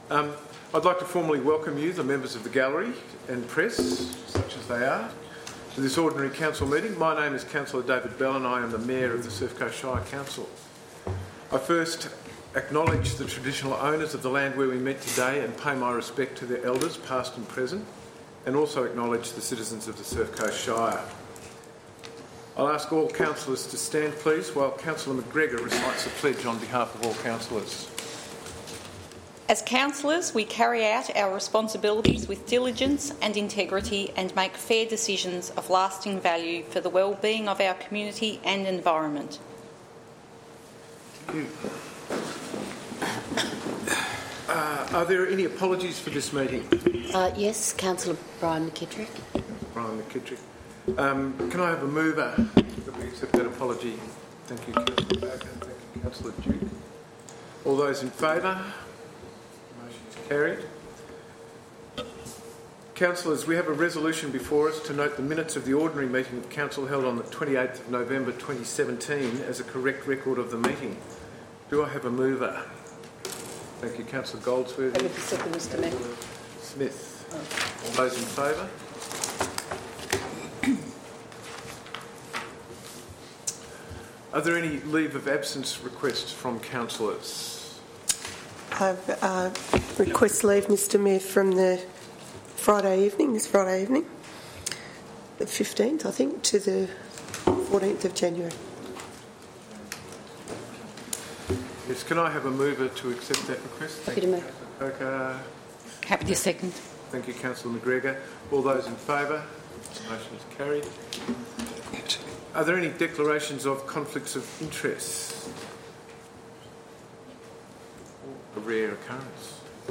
Council Meeting 12 December 2017